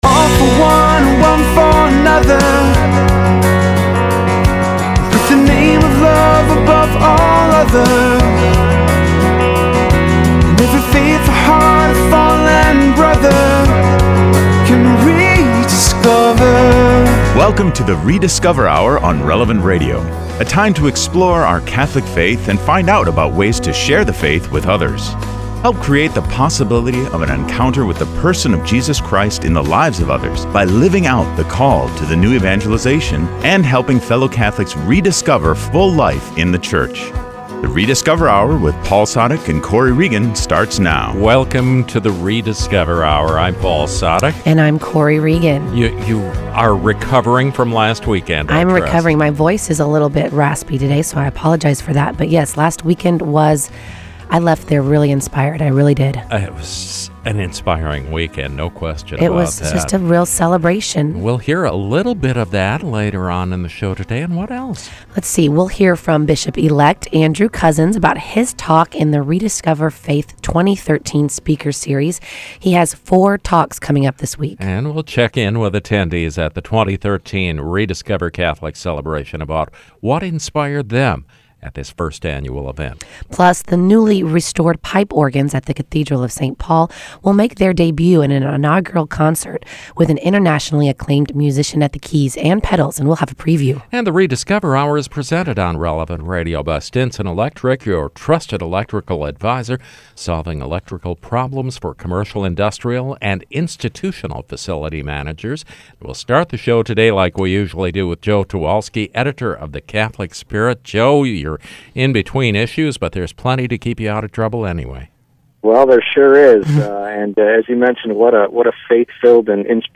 On The Rediscover: Hour, we speak with Bishop-elect Andrew Cozzens about his talk in the Rediscover: faith Speakers Series.
And we’ll hear from folks who attended the 2013 Rediscover: Catholic Celebration . Plus, the newly restored pipe organs at the Cathedral of Saint Paul will make a debut in an inaugural concert – we’ll have a preview.